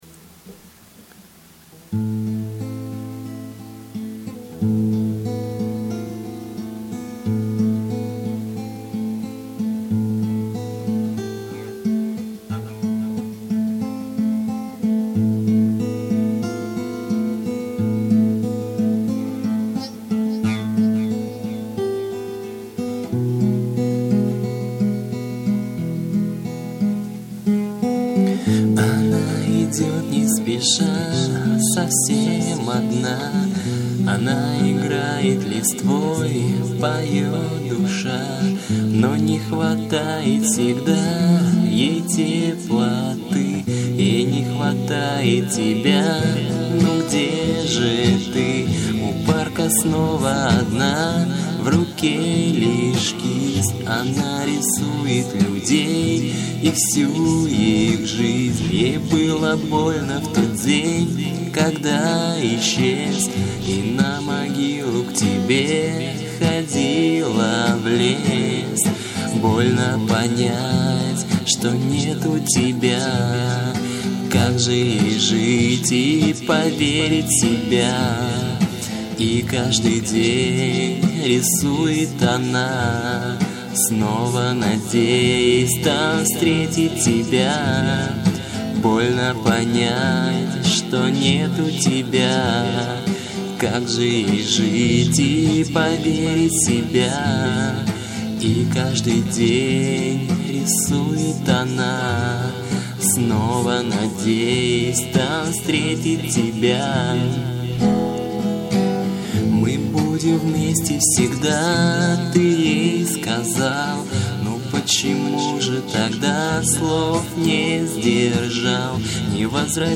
гитарная версия